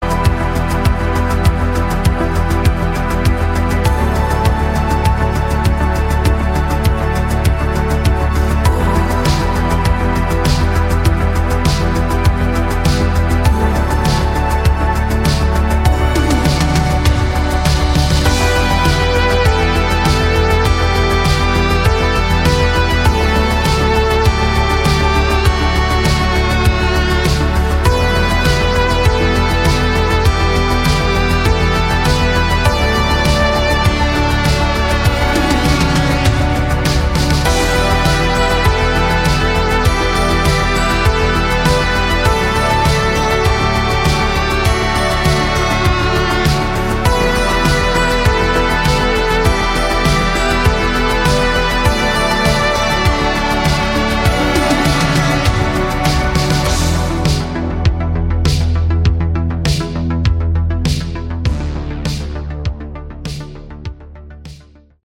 Category: Melodic Rock
vocals, guitars, bass, keyboards
drums, bass, guitar, keyboards